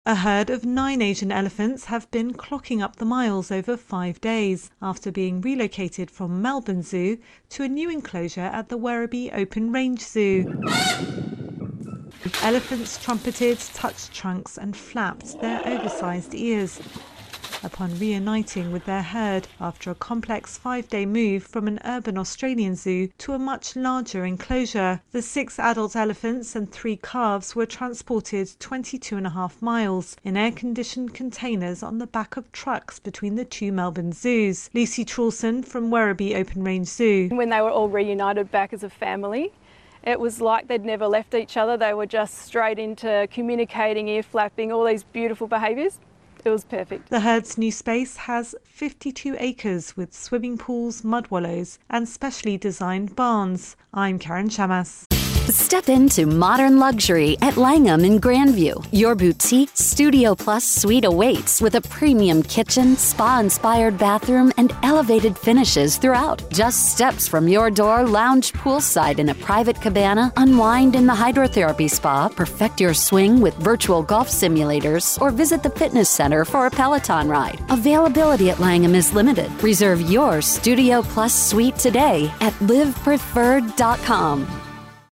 Elephants trumpet, squeak and flap their ears after their complex move across an Australian city